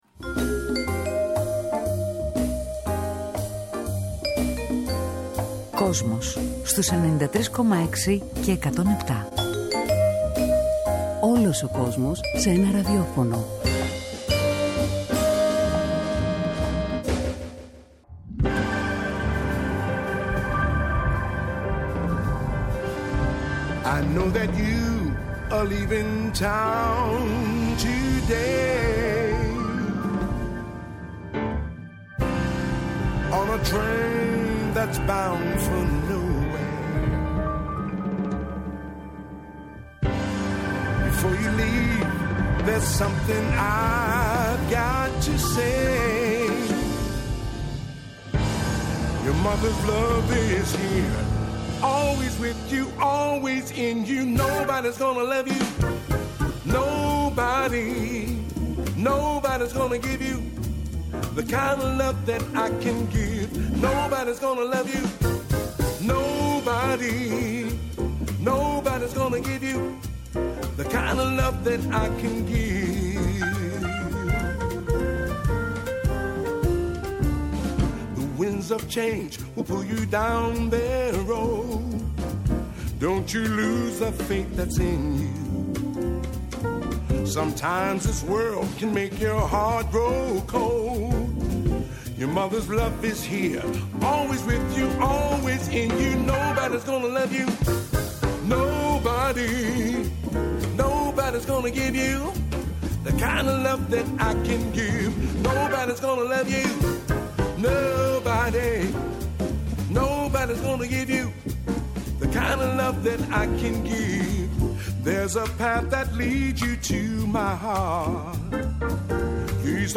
σήμερα φιλοξενούν live στο στούντιο τον αμερικανό τραγουδιστή και κιθαρίστα Allan Harris που εμφανίζεται και στο Half Note Jazz Club
Συνεντεύξεις